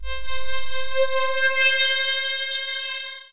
Rising Sweep C4.wav